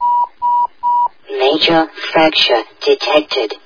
Download Half Life HEV Fracture sound effect for free.